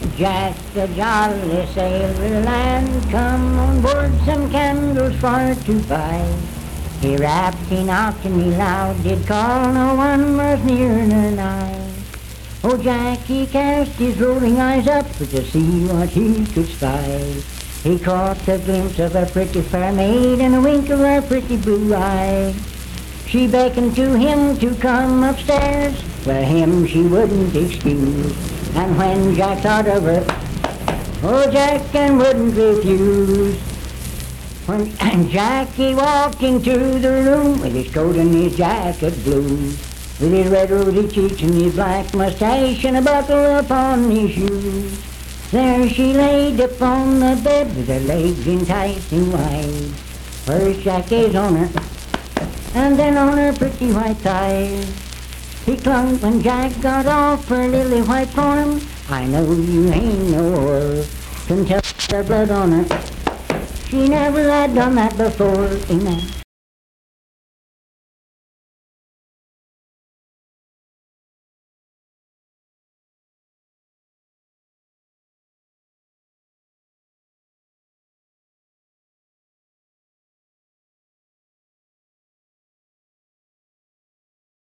Unaccompanied vocal music
Verse-refrain 6(4). Performed in Sandyville, Jackson County, WV.
Bawdy Songs
Voice (sung)